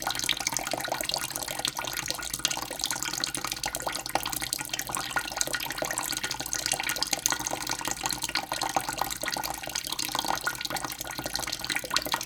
water_dripping_running_01_loop.wav